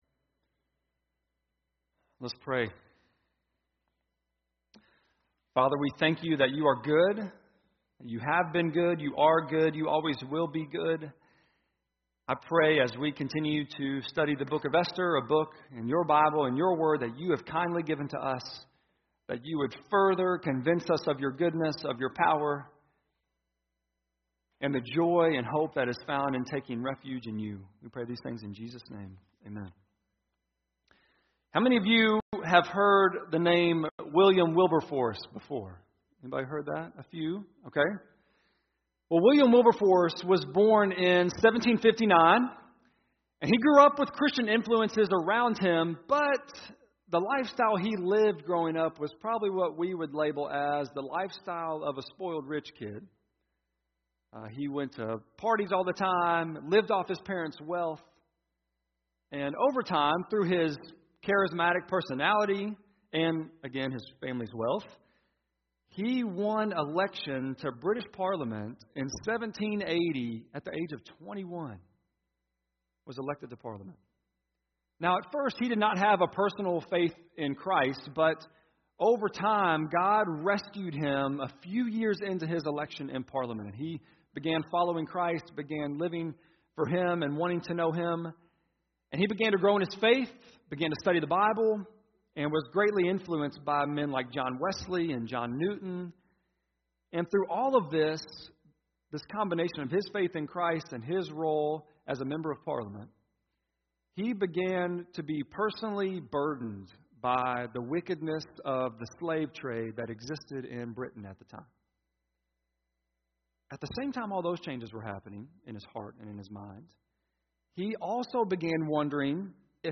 esther-4-sermon.mp3